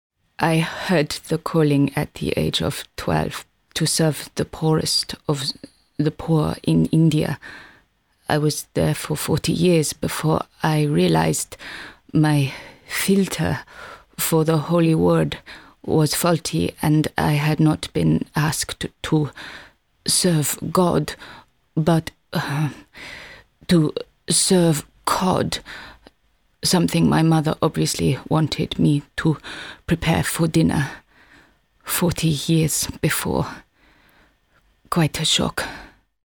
I have a naturally husky, sexy mid tone.
and something to make you smile.. Mother Theresa and Boudica pop in!